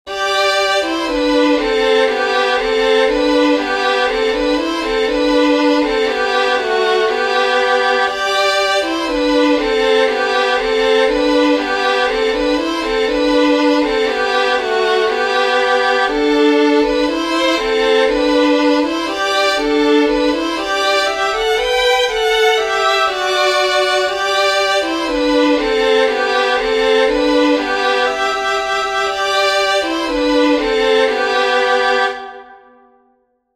Four more Christmas tunes to try at home. If you can find another fiddler to do the harmony, it is even more fun.